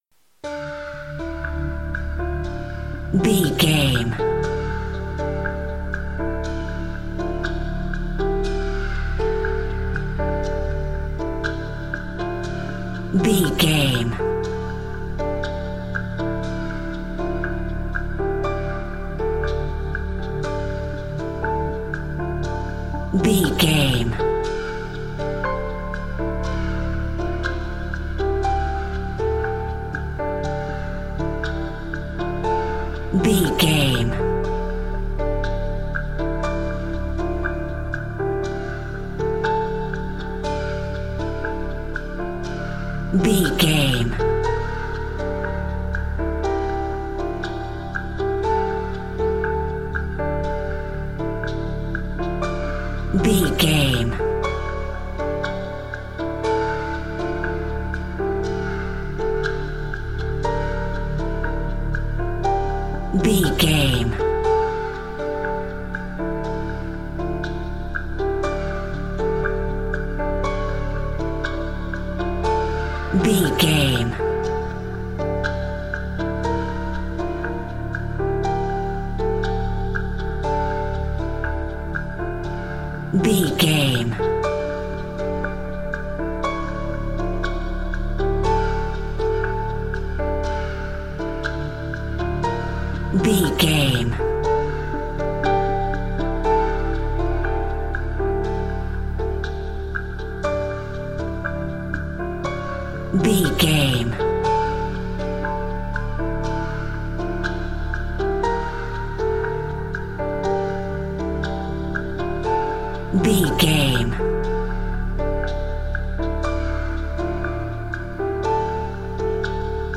Thriller
Aeolian/Minor
D
synthesiser
drum machine
piano
ominous
dark
haunting
creepy